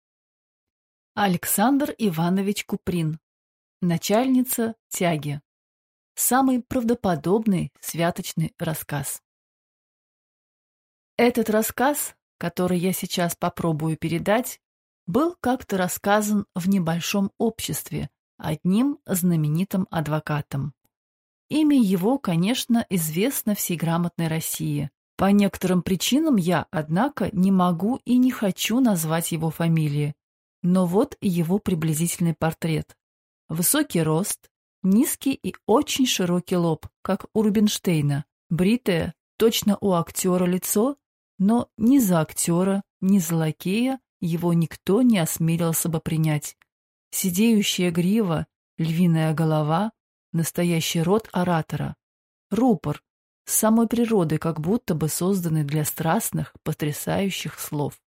Аудиокнига Начальница тяги | Библиотека аудиокниг